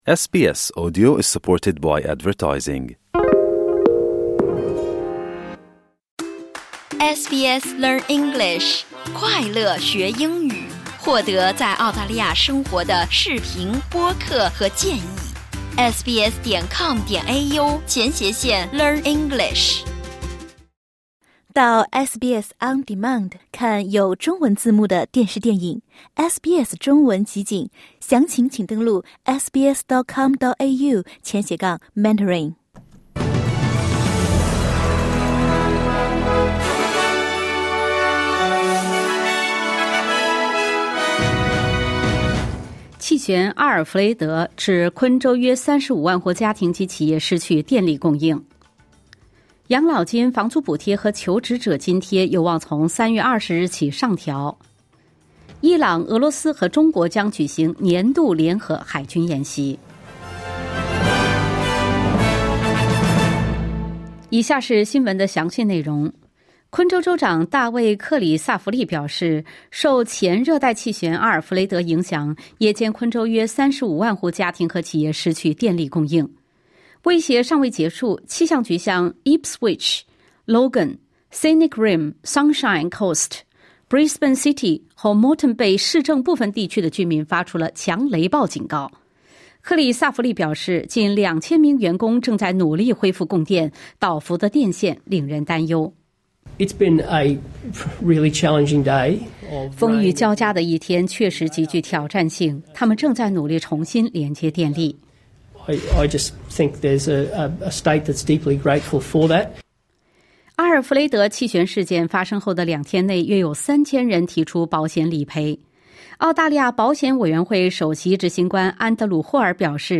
SBS早新闻（2025年3月10日）